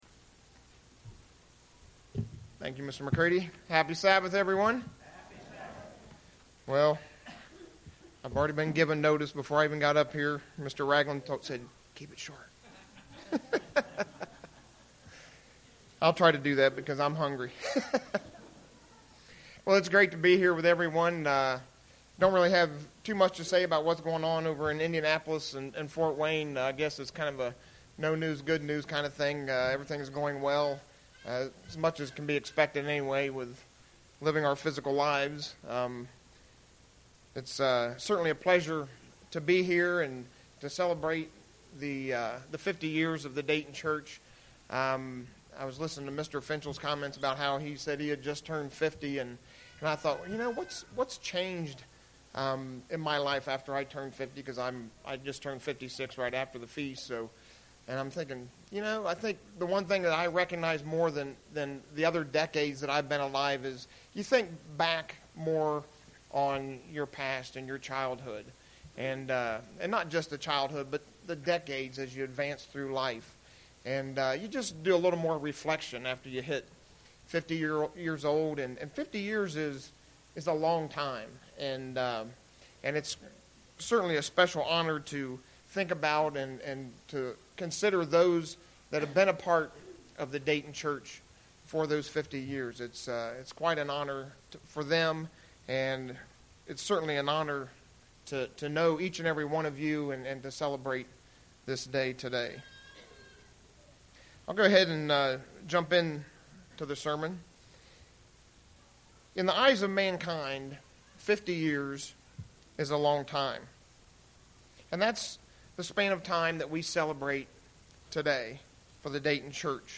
Print Explaining the power of love and its effects on others UCG Sermon Studying the bible?